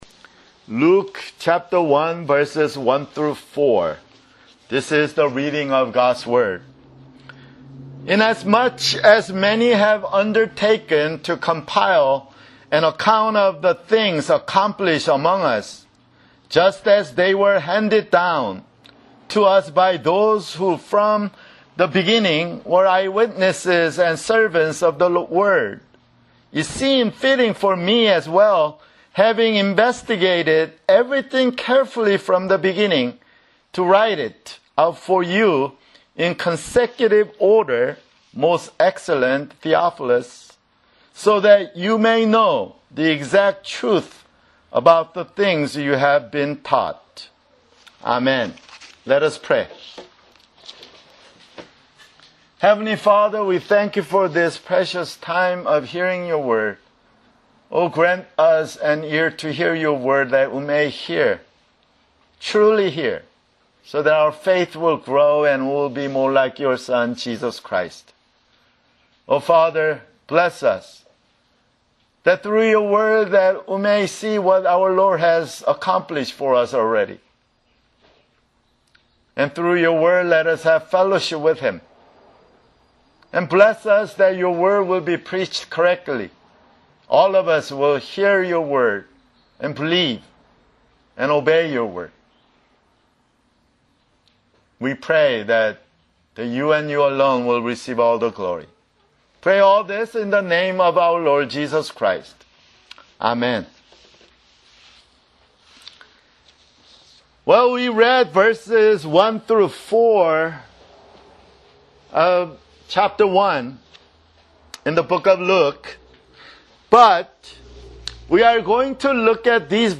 [Sermon] Luke (1)